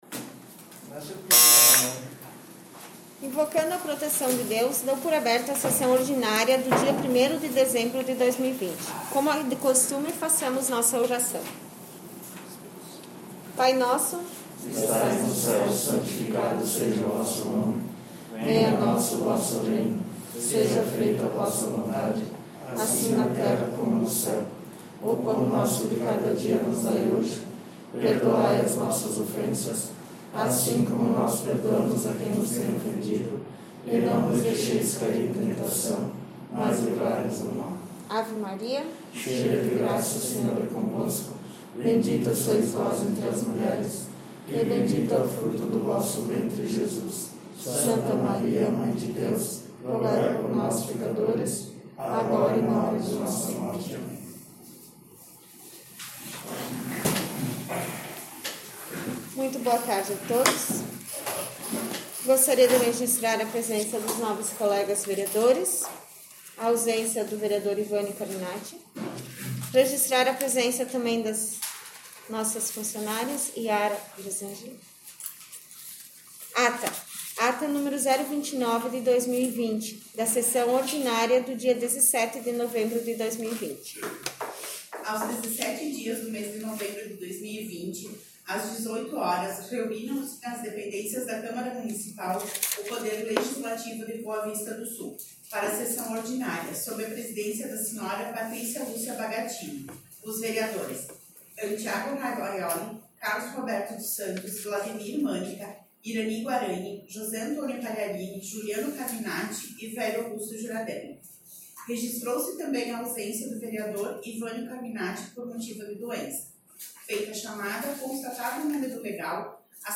Sessão Ordinária 01/12/2020